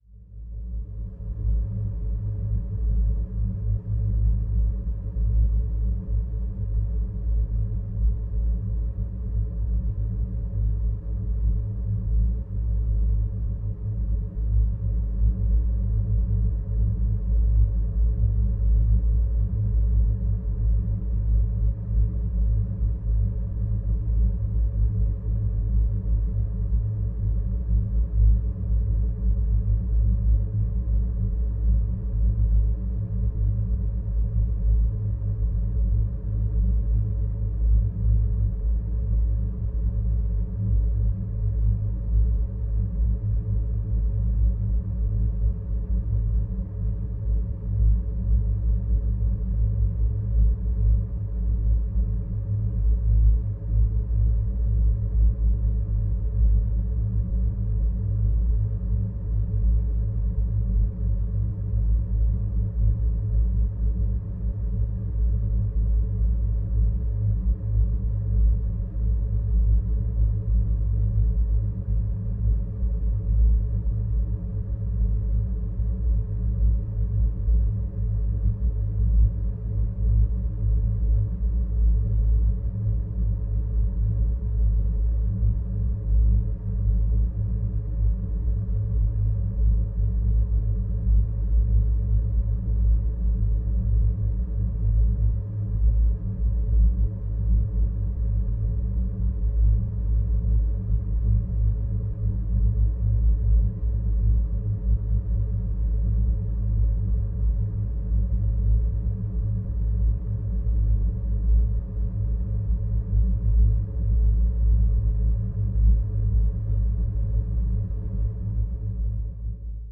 На этой странице собраны звуки пустоты – завораживающие, мистические и медитативные аудиозаписи.
Атмосферный шум пустого пространства